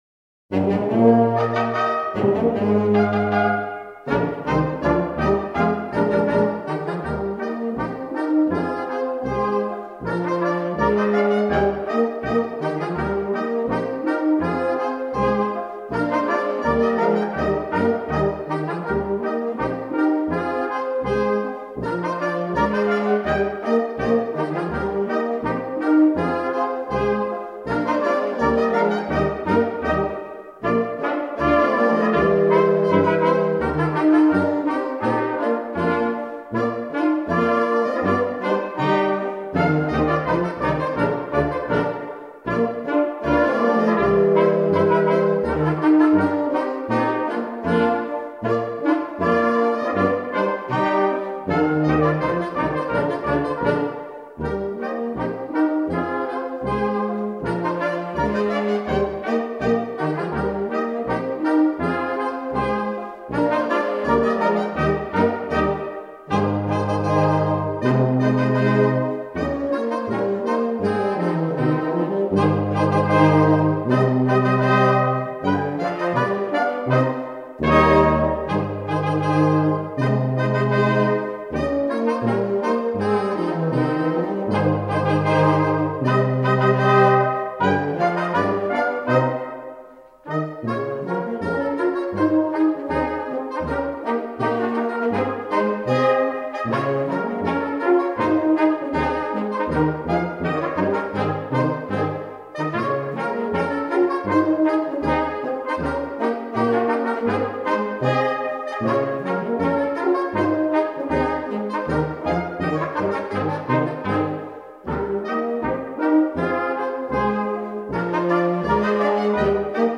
La fine goutte du Chablais: Polka de Taveyannaz – Taveyannaz polka